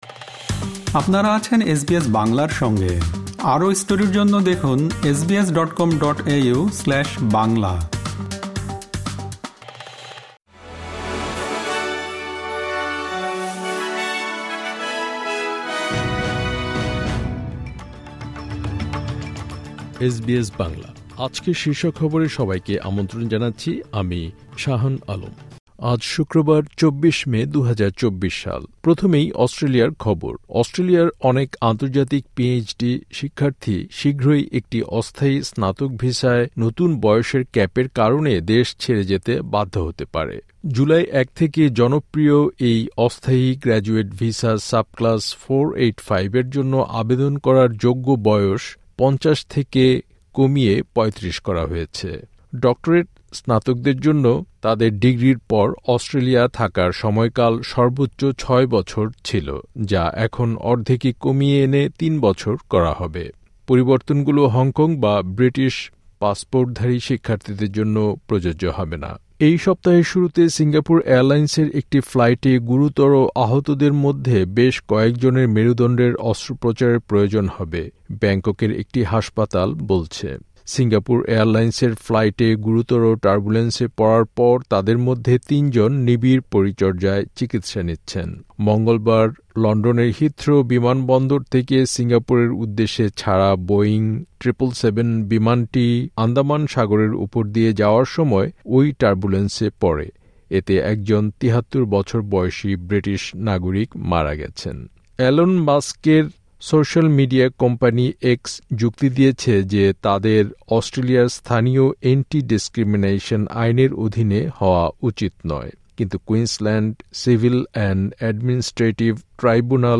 আজকের শীর্ষ খবর অস্ট্রেলিয়ার অনেক আন্তর্জাতিক পিএইচডি শিক্ষার্থী শীঘ্রই একটি অস্থায়ী স্নাতক ভিসায় নতুন বয়সের ক্যাপের কারণে দেশ ছেড়ে যেতে বাধ্য হতে পারে।